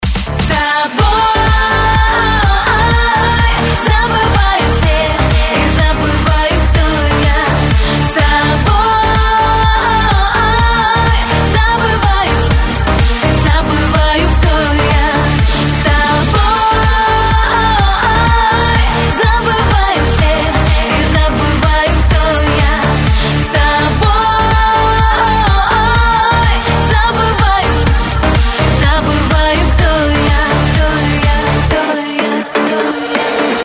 женский вокал
попса